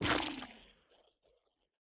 Minecraft Version Minecraft Version 1.21.5 Latest Release | Latest Snapshot 1.21.5 / assets / minecraft / sounds / block / sculk / break14.ogg Compare With Compare With Latest Release | Latest Snapshot
break14.ogg